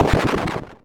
explosion-a.ogg